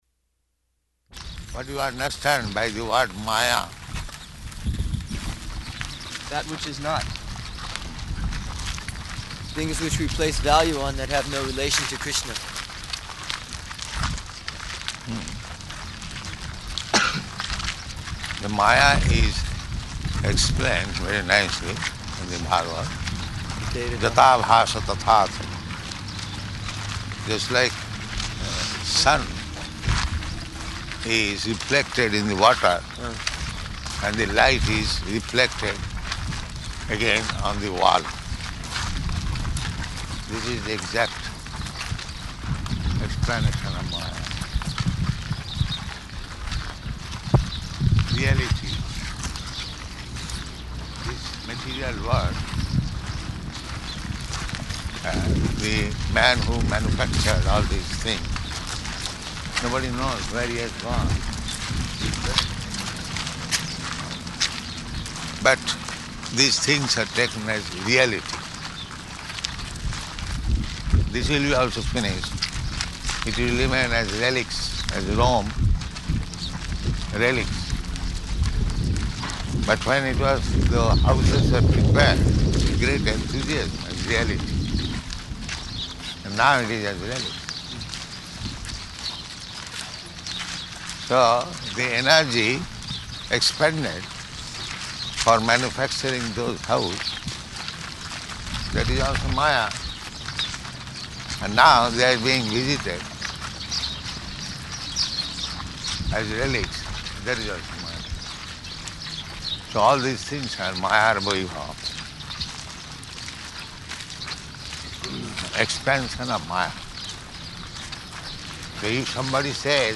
Morning Walk --:-- --:-- Type: Walk Dated: June 13th 1974 Location: Paris Audio file: 740613MW.PAR.mp3 Prabhupāda: What do you understand by the word māyā?